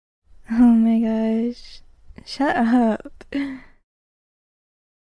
Omg Shut Up Meme Sound sound effects free download
Omg Shut Up Meme Sound Effect